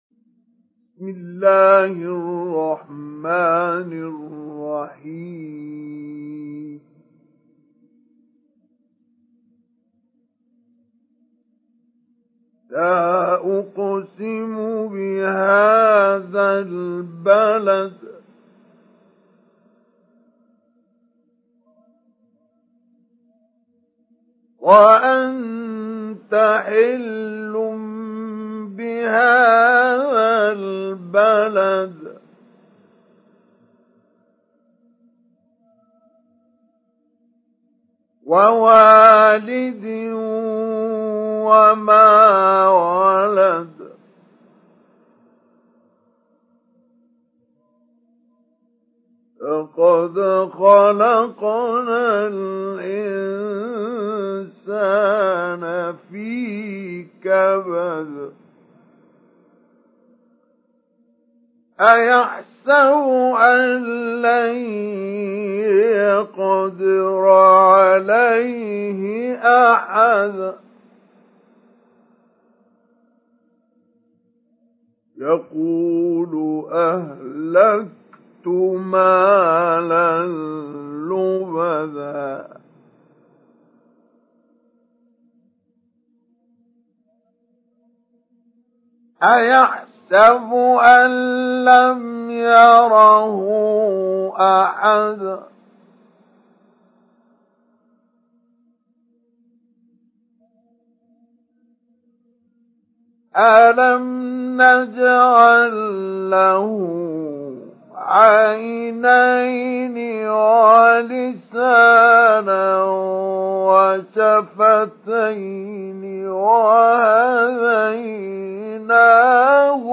تحميل سورة البلد mp3 بصوت مصطفى إسماعيل مجود برواية حفص عن عاصم, تحميل استماع القرآن الكريم على الجوال mp3 كاملا بروابط مباشرة وسريعة
تحميل سورة البلد مصطفى إسماعيل مجود